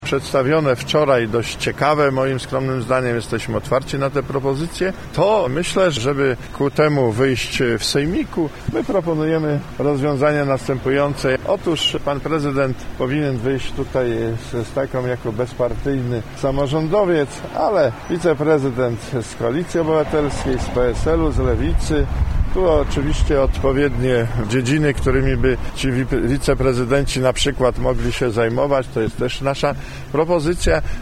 Po jednym wiceprezydencie dla Platformy Obywatelskiej, PSL-u i Lewicy – z taką propozycją wyszli dziś na konferencji prasowej ludowcy, ktorzy chcą, aby Janusz Kubicki podzielił się władzą w mieście. To odpowiedź PSL na propozycję zmian w zarządzie województwa lubuskiego.
Stanisław Tomczyszyn, wicemarszałek lubuski mówił dziś, że pomysł prezydenta Kubickiego i posła Mejzy jest wart rozważenia: